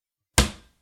关闭橱柜抽屉高音调
描述：关闭一个小而高的音箱。